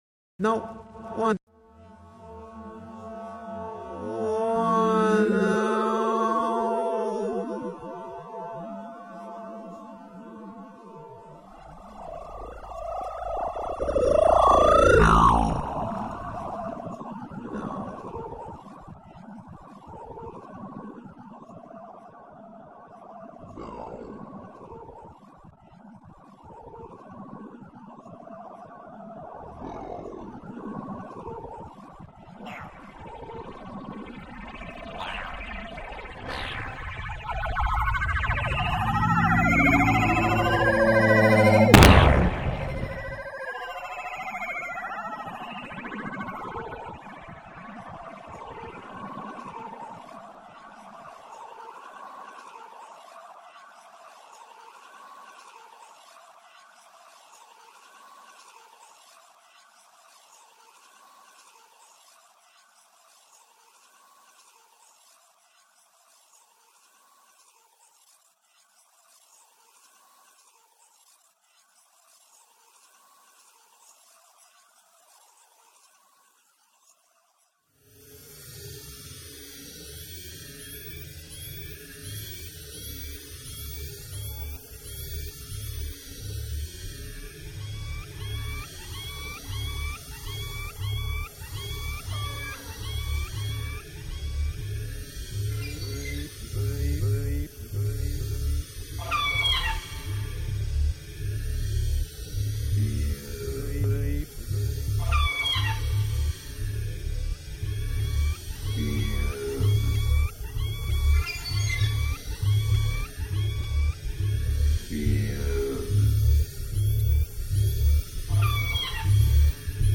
Kantaesitys Helsingiss� Valkoisessa salissa 1995